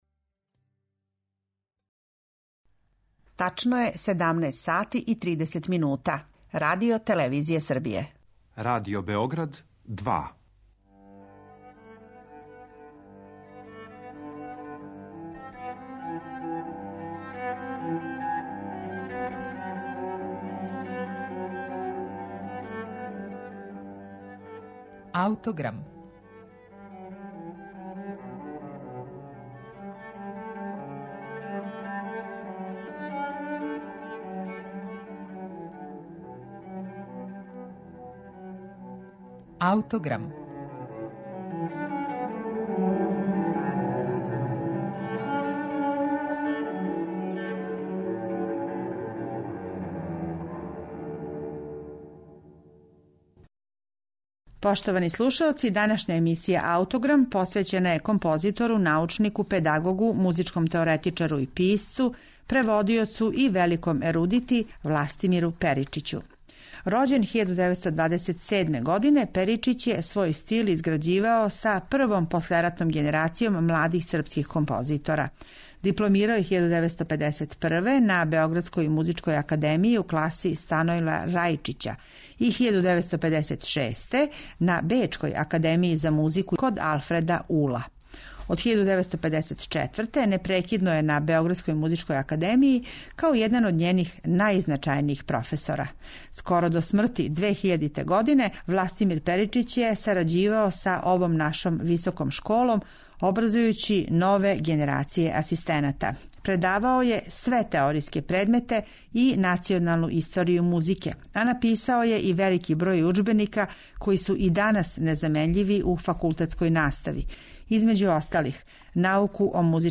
циклусе соло песама
баритон